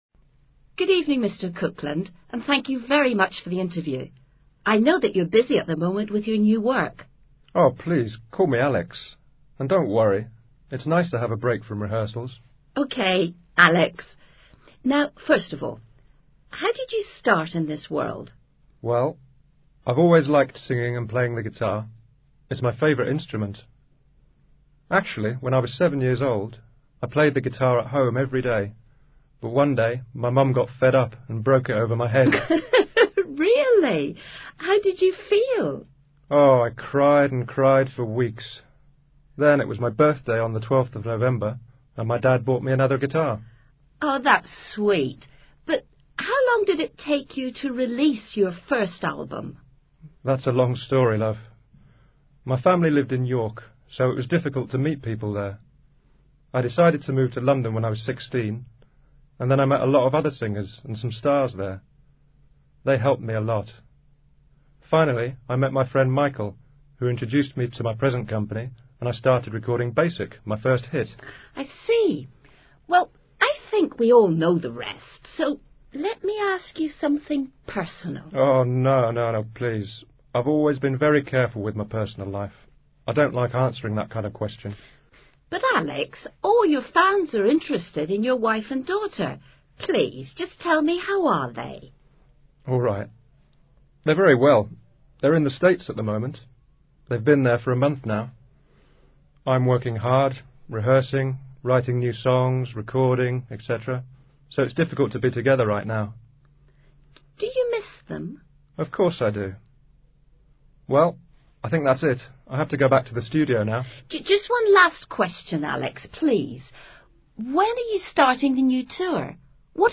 Diálogo que recrea una entrevista entre una periodista y un músico famoso.
Lenguaje hablado